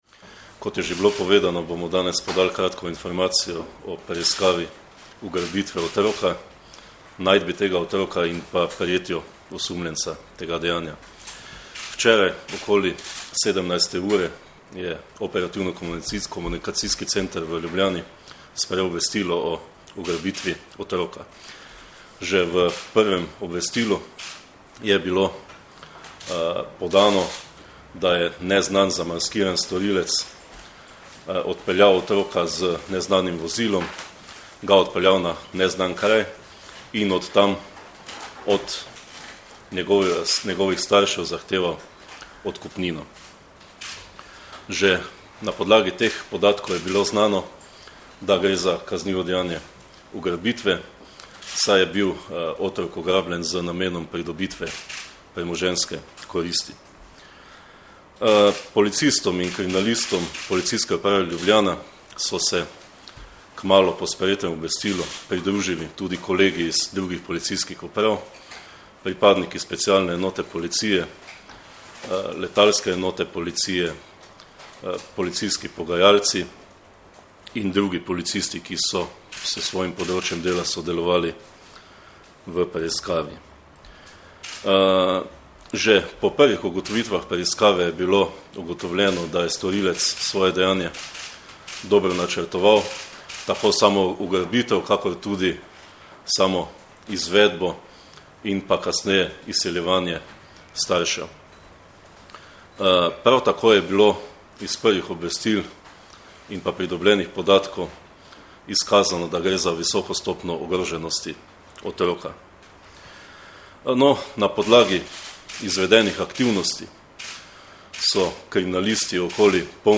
Zvočni posnetek izjave pomočnika direktorja Uprave kriminalistične policije Marjana Fanka (mp3)